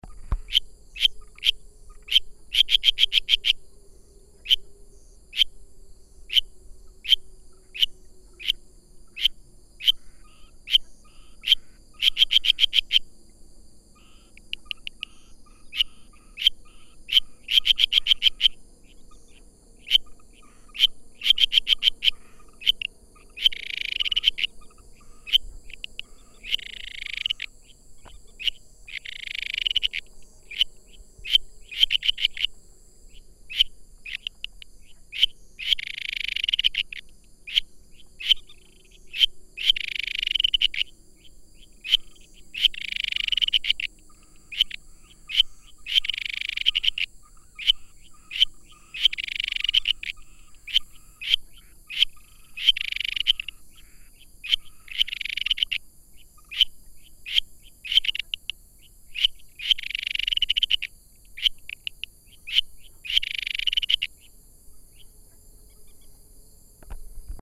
日本樹蛙 Buergeria japonica
花蓮縣 光復鄉 大富
造林地內暫時性積水
5隻以上競叫